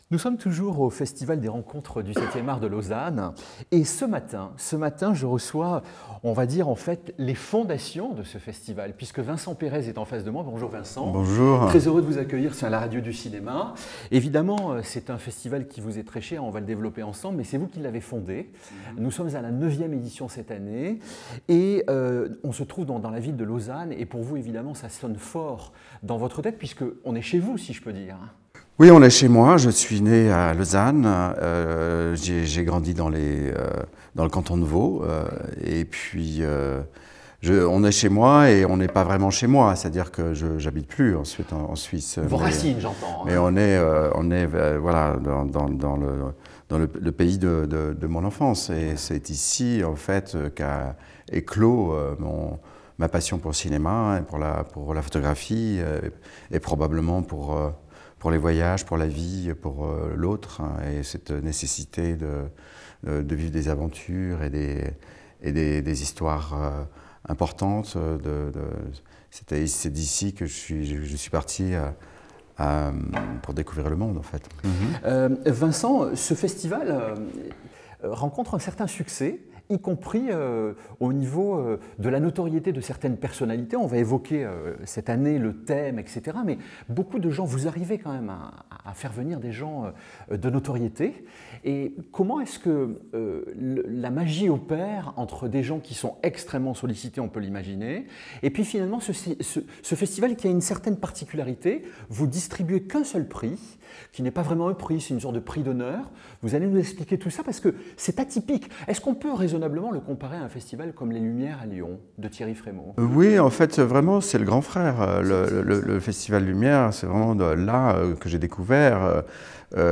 À l’occasion de la neuvième édition des Rencontres du 7e art Lausanne, Vincent Perez se confie au micro de La Radio du Cinéma : « On est chez moi », glisse-t-il. Du 5 au 15 mars 2026, la ville déroule le tapis rouge au cinéma du patrimoine, aux masterclass et à une thématique qui sonne comme un mot de passe : HOPE.